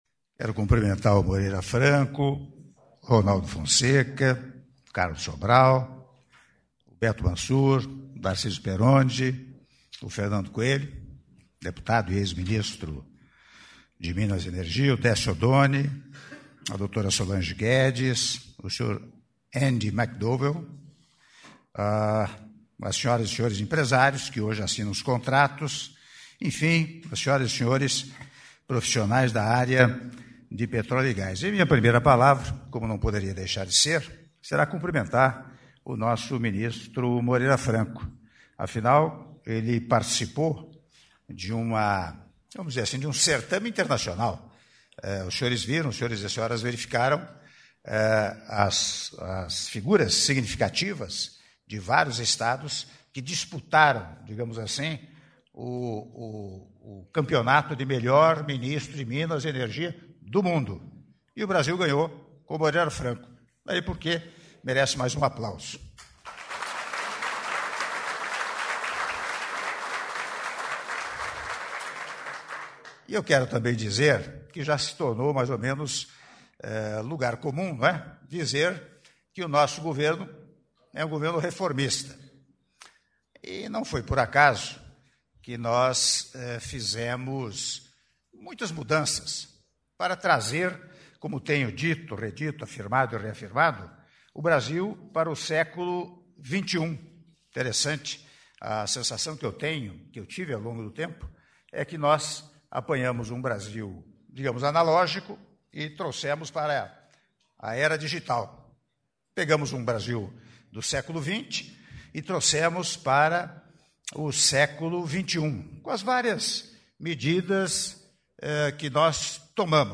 Áudio do discurso do Presidente da República, Michel Temer, durante solenidade Avanços no Setor Óleo e Gás 2016-2018 - Palácio do Planalto (07min23s)